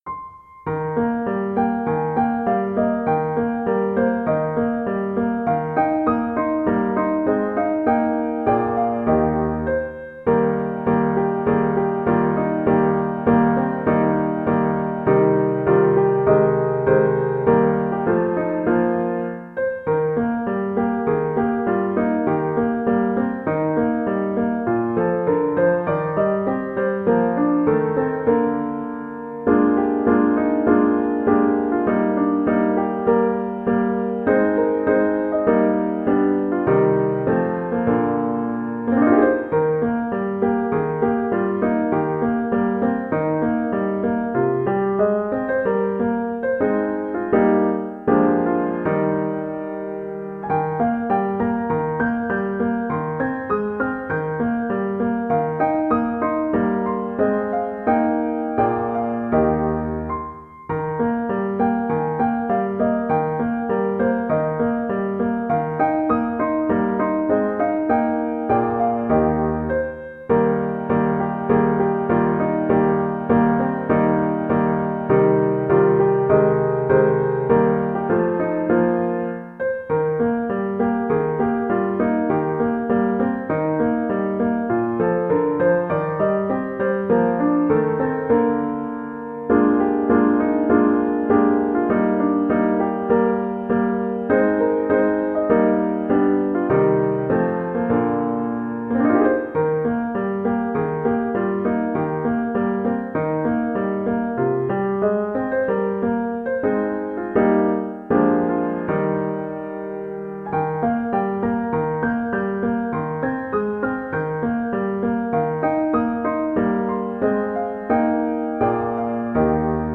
No parts available for this pieces as it is for solo piano.
4/4 (View more 4/4 Music)
Moderato